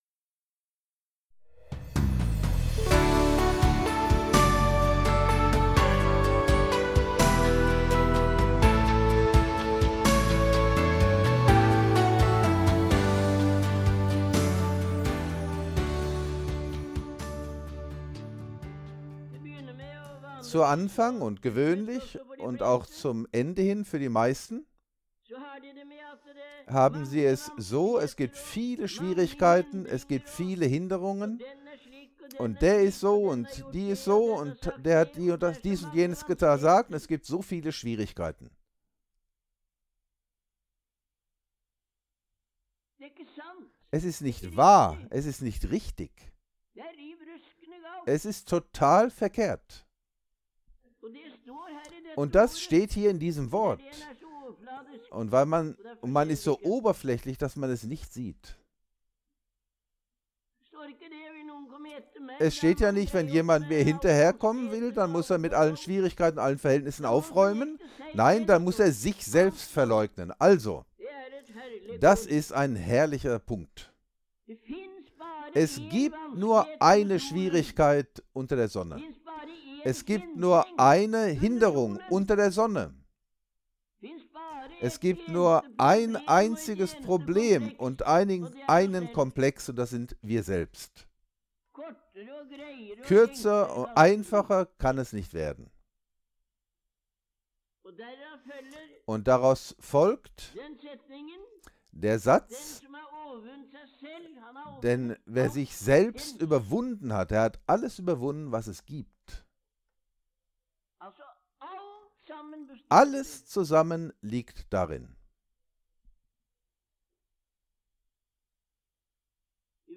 Auszug der Redevon Januar 1973